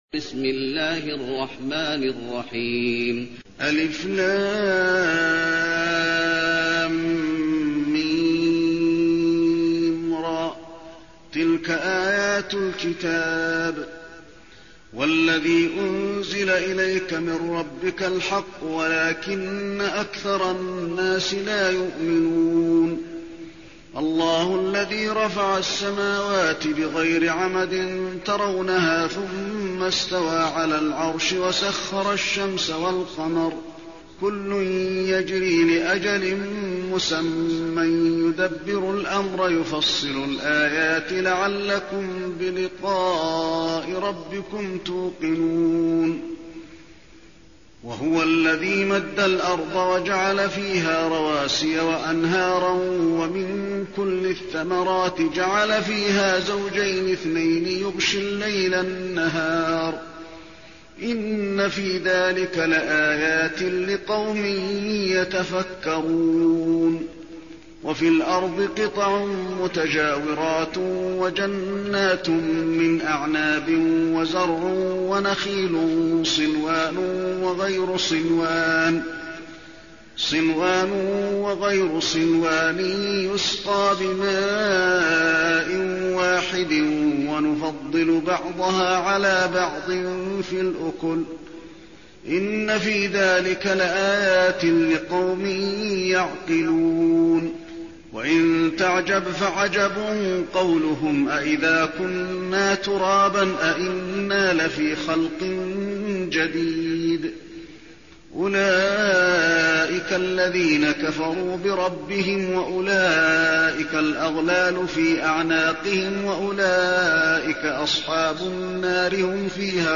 المكان: المسجد النبوي الرعد